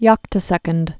(yŏktə-sĕkənd)